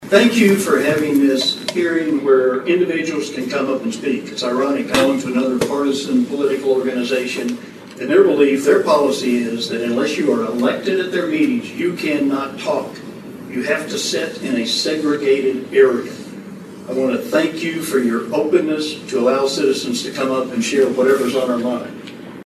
Most in attendance at Monday's City Council meeting spoke out against the resolution by the Washington County GOP's County Committee and reiterated their support of the City Council and city staff.
Former state Sen. John Ford, who is a Republican and a member of the Washington County GOP, thanked the City Council for allowing an open forum, and wished the Washington County GOP County Committee would
John Ford on openess REVISED 8-7.mp3